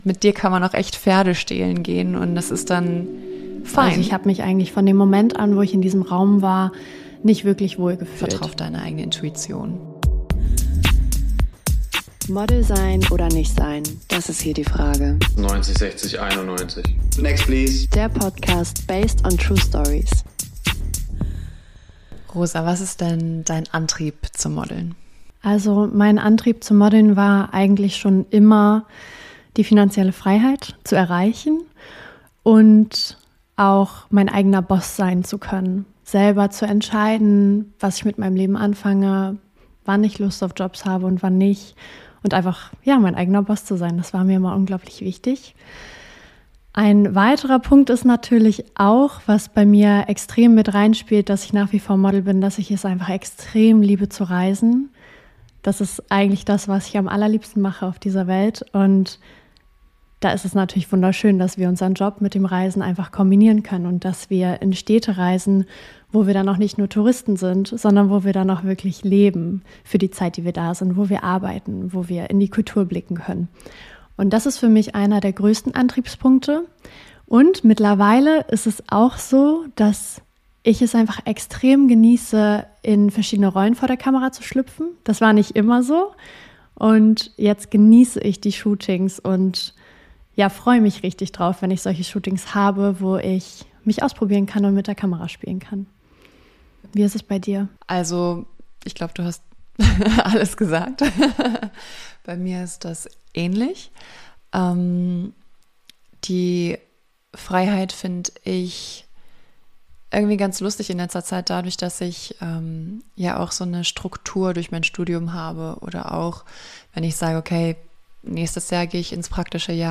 Ein ehrliches, lebendiges Gespräch – wie ein Abend unter Freunden, nur mit Mikrofon.